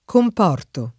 comportare v.; comporto [ komp 0 rto ]